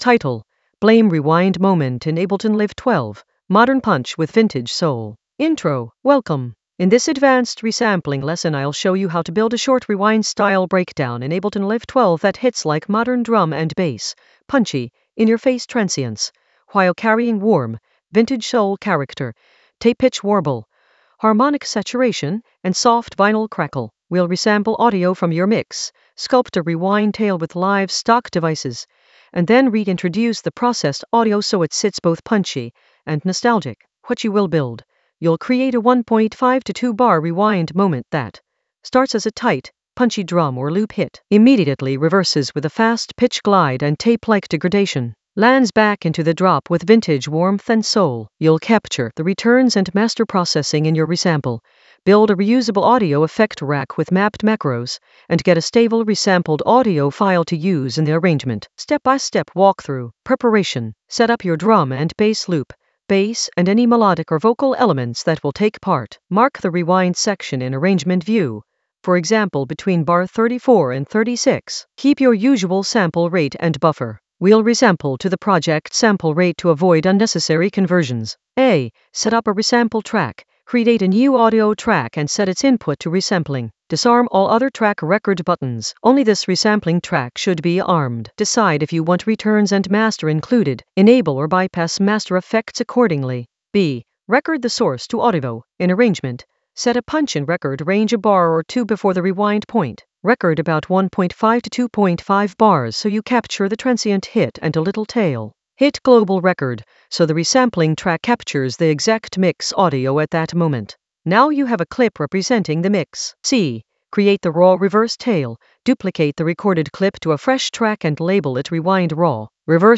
An AI-generated advanced Ableton lesson focused on Blame rewind moment in Ableton Live 12 for modern punch and vintage soul in the Resampling area of drum and bass production.
Narrated lesson audio
The voice track includes the tutorial plus extra teacher commentary.